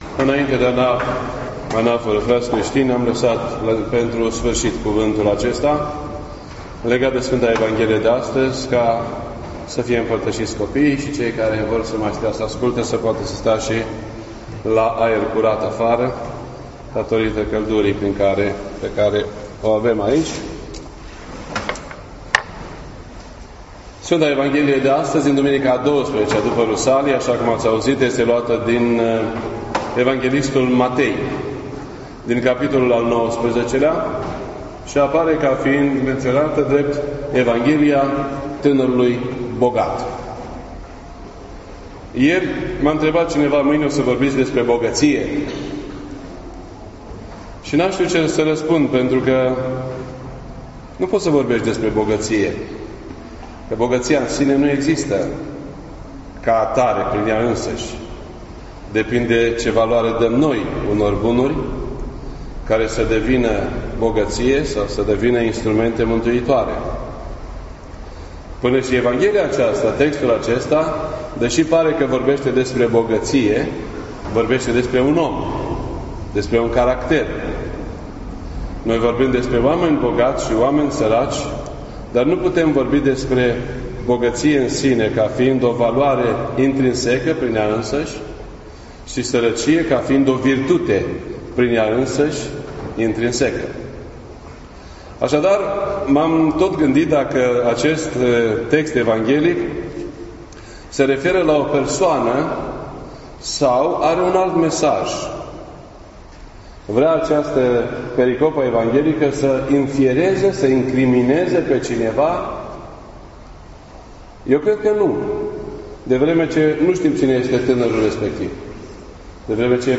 This entry was posted on Sunday, August 27th, 2017 at 7:06 PM and is filed under Predici ortodoxe in format audio.